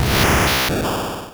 Cri d'Abra dans Pokémon Rouge et Bleu.